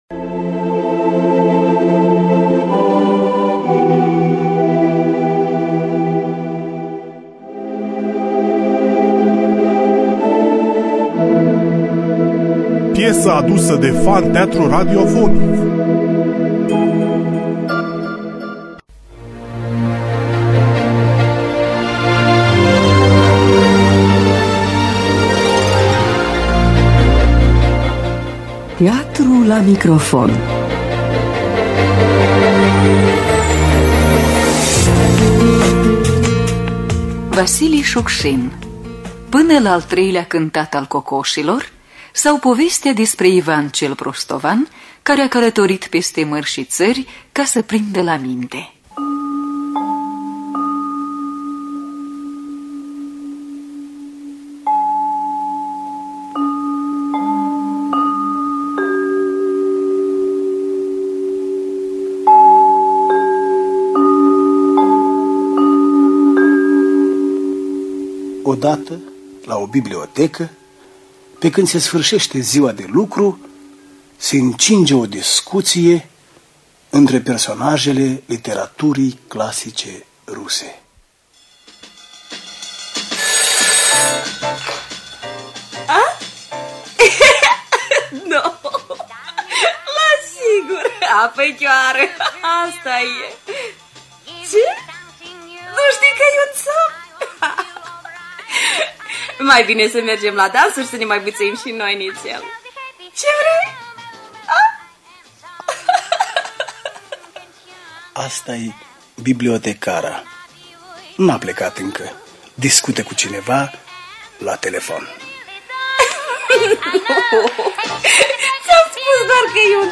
Vasili Suksin – Pana La Al Treilea Cantat Al Cocosilor (1983) – Teatru Radiofonic Online